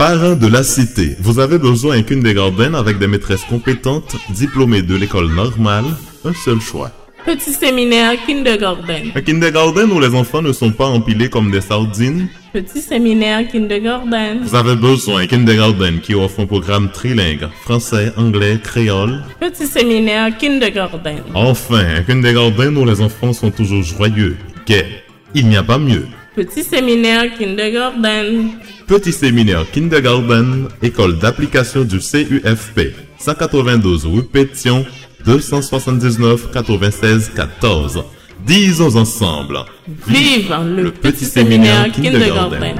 Si vous êtes à la recherche de voix gutturale, énergique, imposante, timbrée ayant une parfaite résonance, vous êtes sur la bonne voie/voix.
Démo